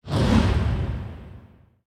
archer_skill_pinpointshot_intro_a.ogg